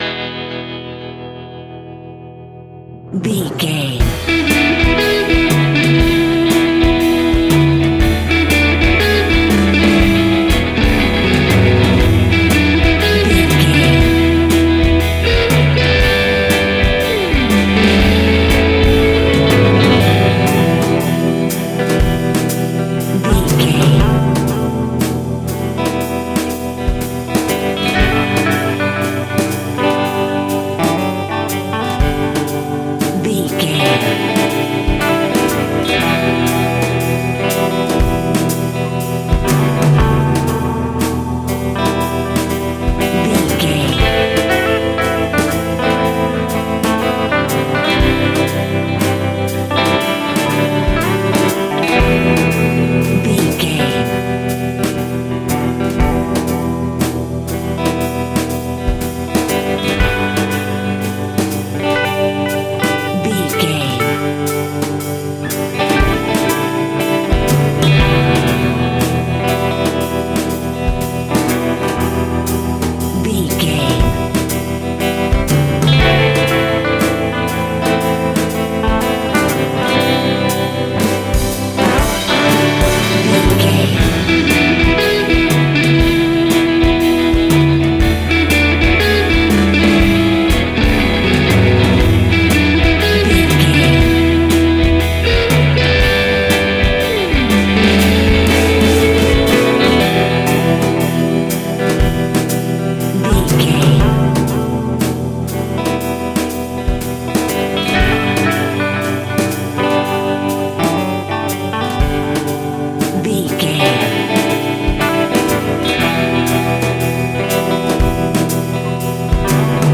Aeolian/Minor
cool
uplifting
bass guitar
electric guitar
drums
cheerful/happy